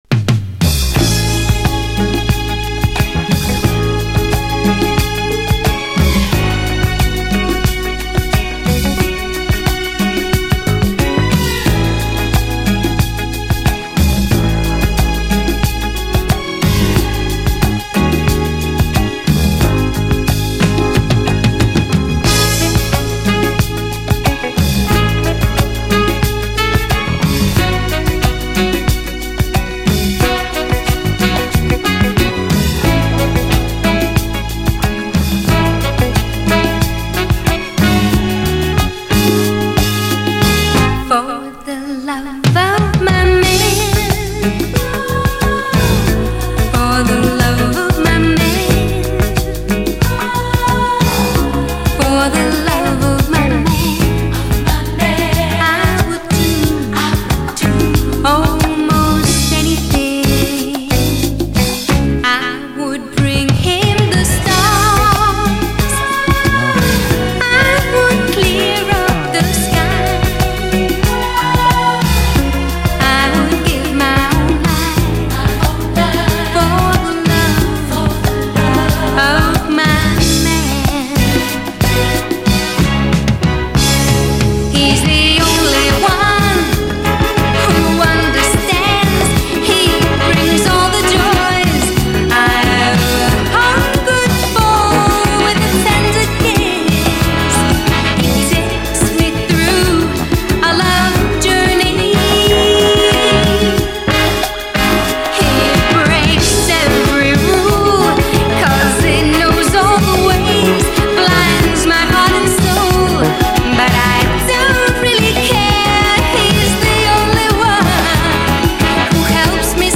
SOUL, 70's～ SOUL